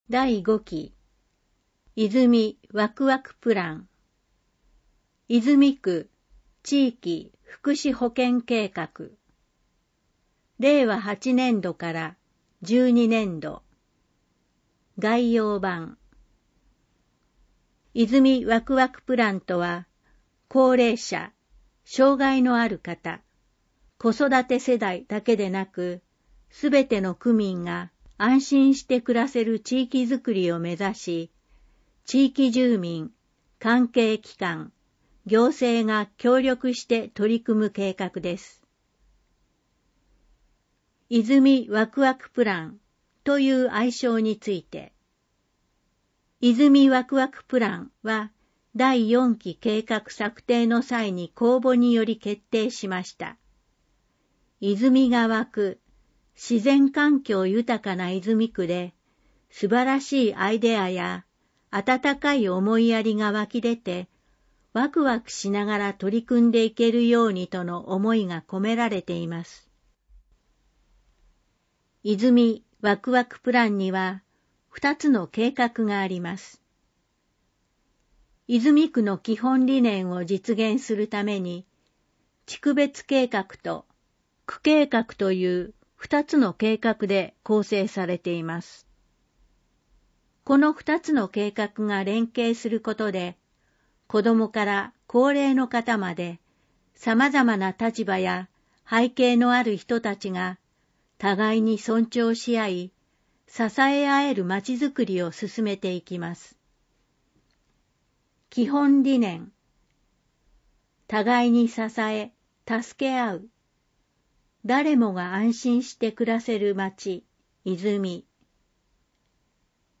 第5期泉わくわくプラン（泉区地域福祉保健計画）音声訳版（音楽ファイル(MP3)：4,542KB）